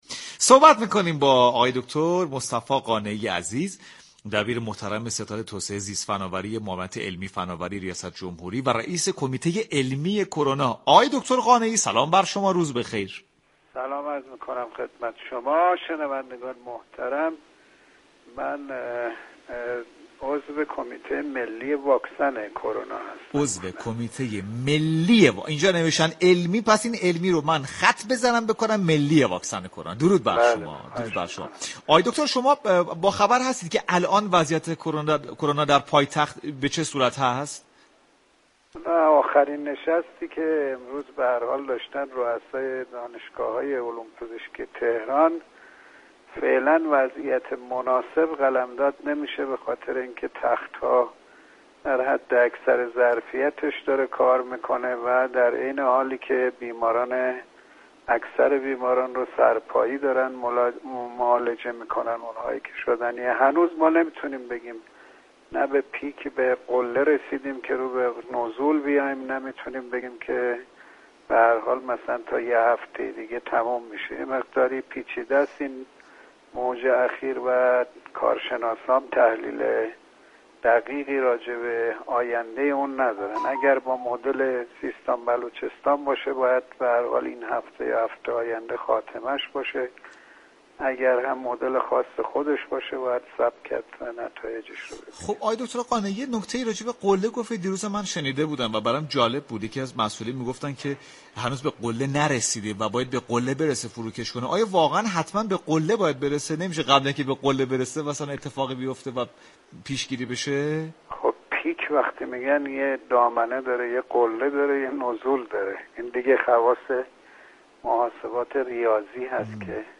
به گزارش پایگاه اطلاع رسانی رادیو تهران، دكتر مصطفی قانعی عضو كمیته ملی واكسن كرونا در گفتگو با برنامه سعادت آباد رادیو تهران در روز شنبه 9 مرداد با اشاره به آخرین جلسه روسای دانشگاه‌های علوم پزشكی تهران اظهار داشت: وضعیت تهران مناسب نیست.